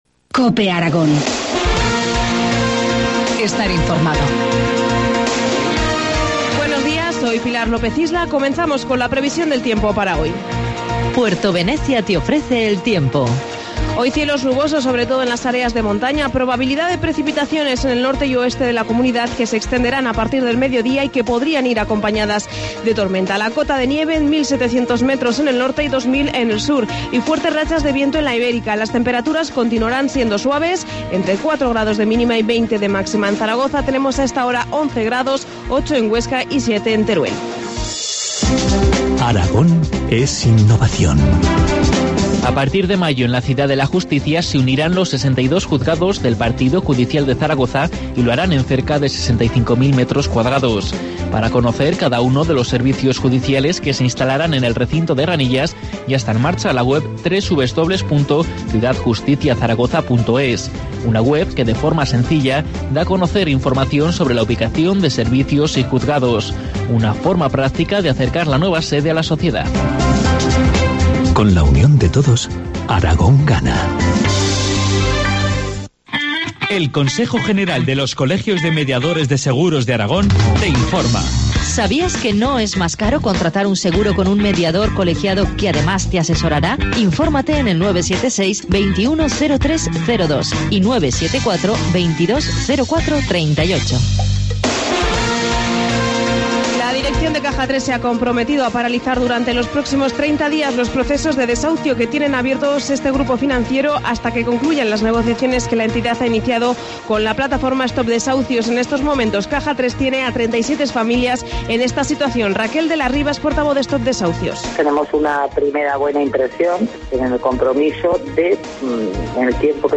Informativo matinal, martes 26 de marzo, 8.25 horas